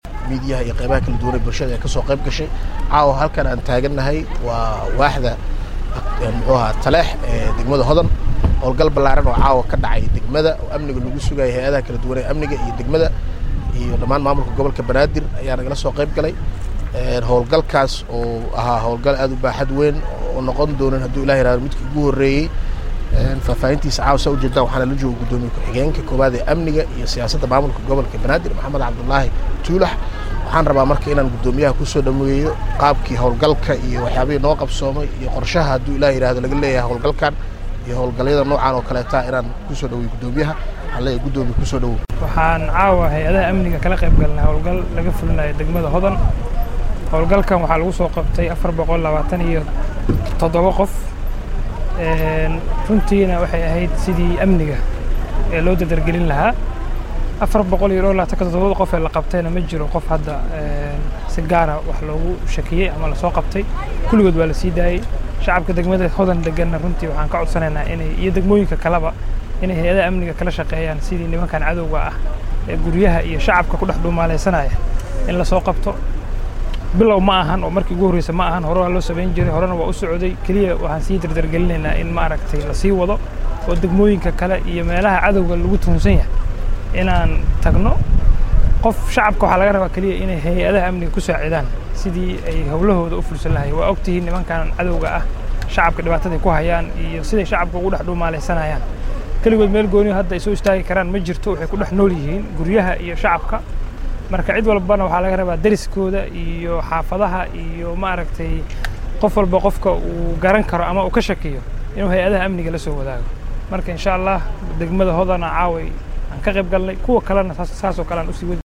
Halkaan hoose ka dhageyso Codadka Guddoomiyaasha:
codadka-hodan.mp3